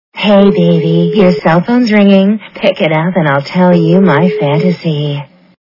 качество понижено и присутствуют гудки.